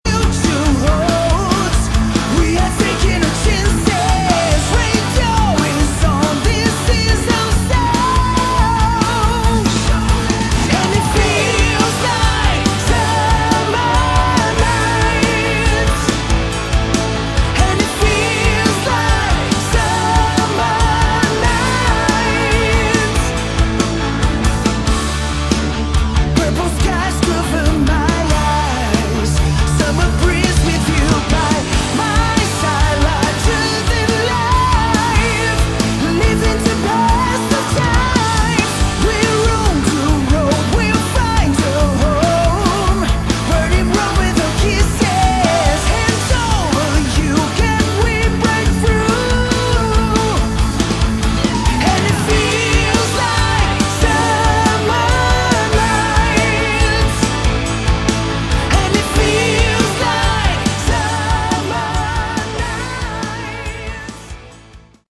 Category: Melodic Hard Rock
vocals
guitars
bass
drums